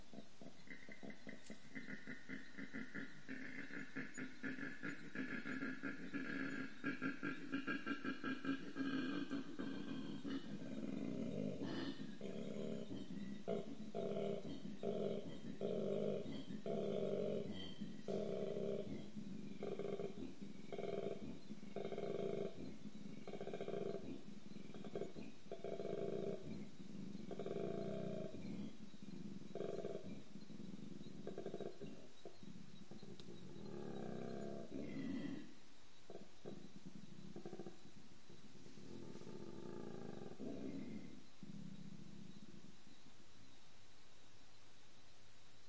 The trusty male koala might be small and cute, but it bellows like a rock star.
The koala mating call
Male koalas bellow to attract females as well as to intimidate other males and get them to leave the immediate area.
Recording of the mating call of a male koala in the Pine Mountain area near Brisbane.
Koala-mating-call-audio.mp3